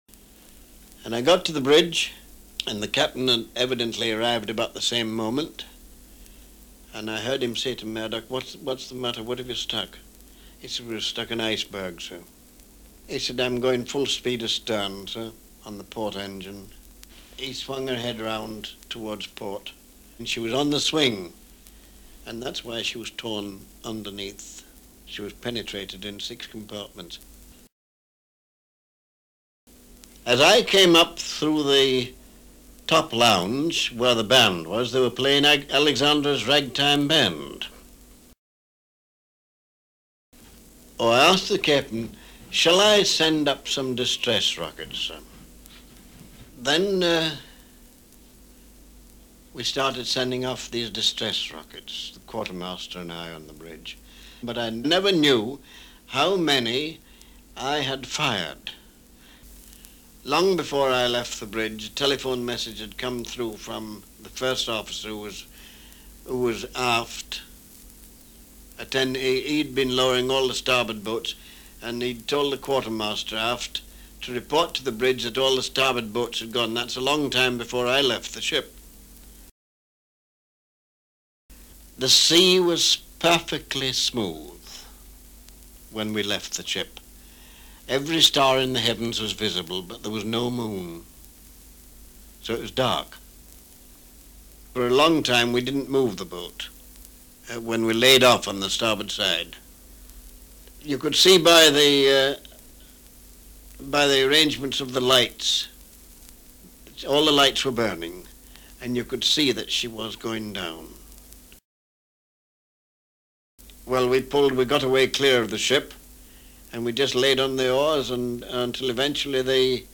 Qui potete ascoltare le voci di chi visse, in prima persona, il disastro del TITANIC nel 1912. Queste registrazioni originali sono state gentilmente concesse dall'Archivio della BBC.
Joseph Groves Boxhall, Quarto Ufficiale del TITANIC;